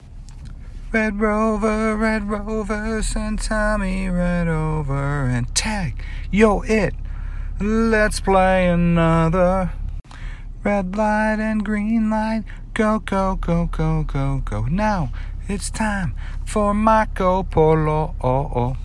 fast d f# b a